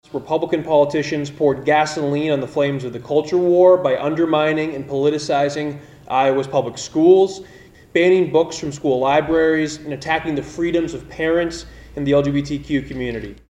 Senate Democratic Leader Zach Wahls says the GOP pursued divisive policies this year.